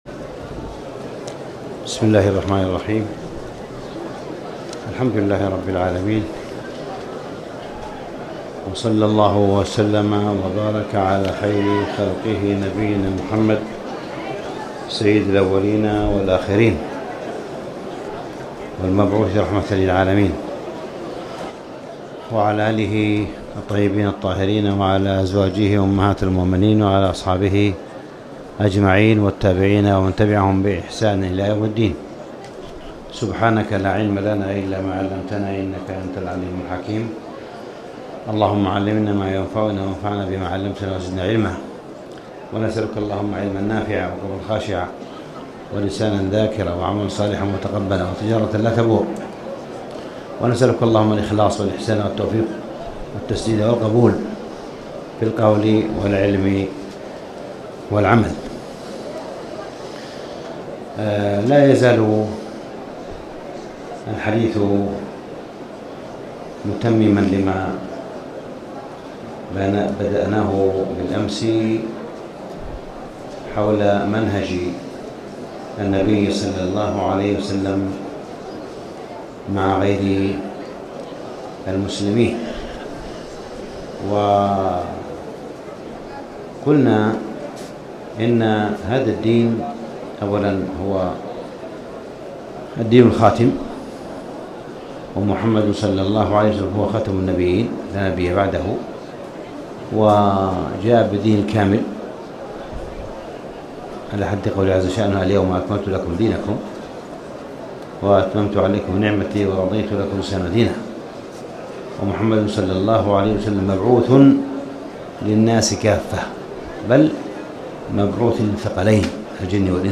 تاريخ النشر ١٧ رمضان ١٤٣٨ هـ المكان: المسجد الحرام الشيخ: معالي الشيخ أ.د. صالح بن عبدالله بن حميد معالي الشيخ أ.د. صالح بن عبدالله بن حميد هدي النبي صلى الله عليه وسلم مع غير المسلم